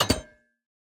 Minecraft Version Minecraft Version latest Latest Release | Latest Snapshot latest / assets / minecraft / sounds / block / copper_door / toggle3.ogg Compare With Compare With Latest Release | Latest Snapshot